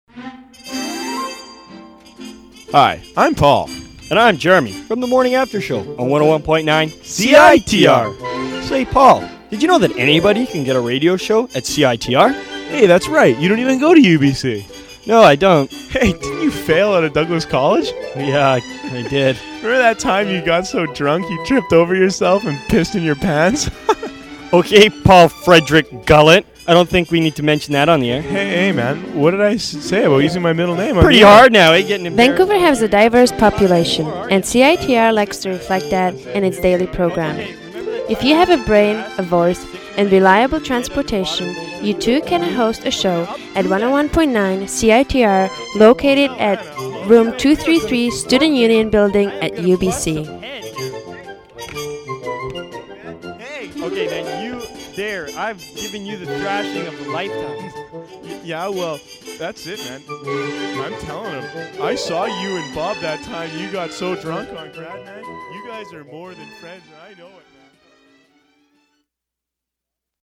Thanks for taking a second to listen to bad old college radio from the late 90s and 2000s.